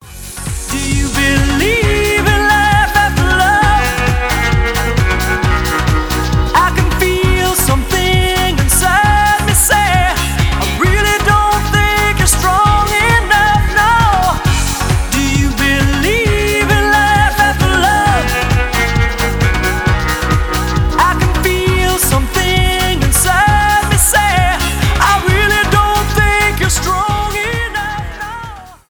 dance pop , евродэнс